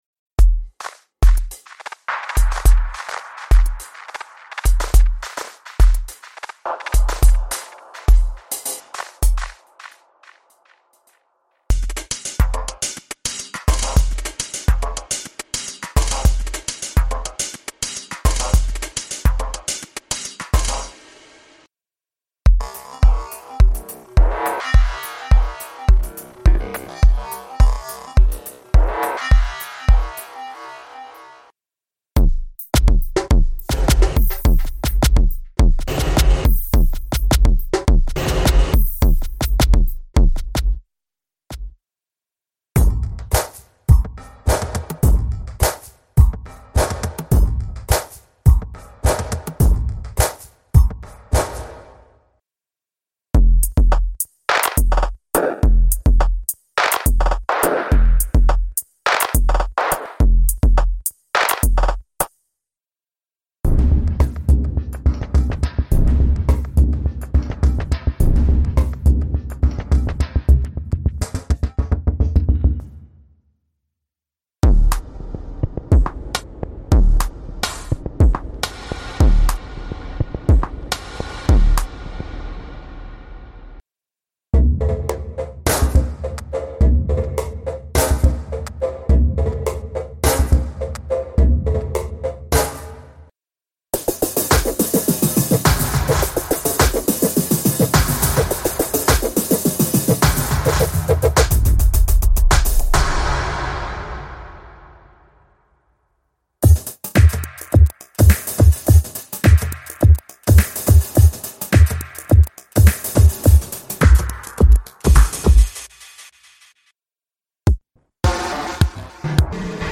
SODIUM 是创意鼓和节拍音序器。
什么 - 200 个鼓舞人心的鼓组 - 丰富的电影、电子、原声和混合鼓组 - 数百个包含的鼓声 - 四个具有 20 个内部预设的音序器 - 模式编辑（复制、粘贴、随机化、初始化、重复）
- 具有回声，驱动，合唱和天赋的多FX引擎 - 内置制作质量板混响 - 多输出路由（将每个鼓声路由到自己的通道）